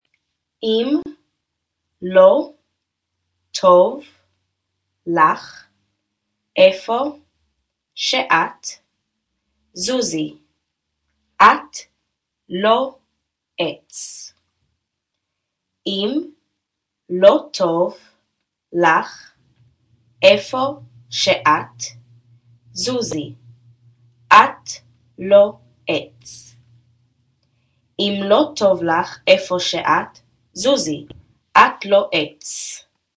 Listen to me read the quote here 3x